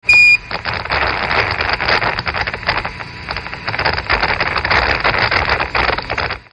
Шипение рации в космической тишине